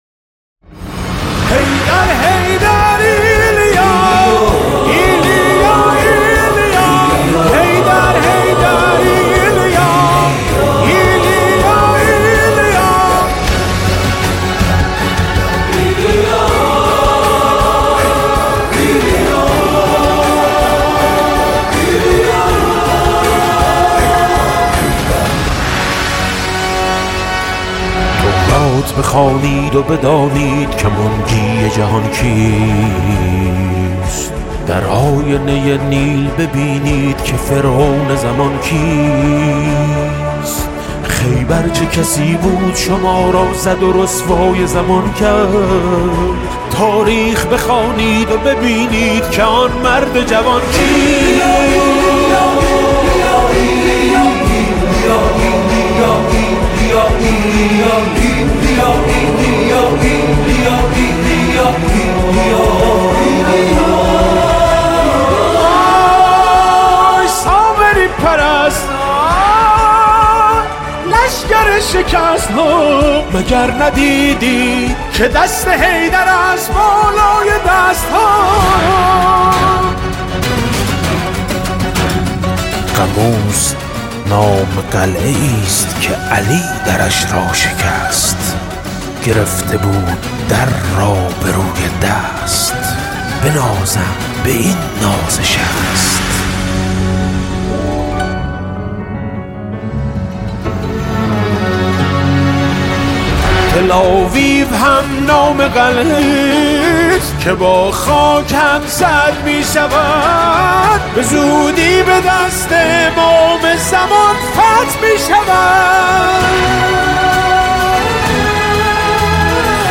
نماهنگ حماسی نماهنگ مقاومت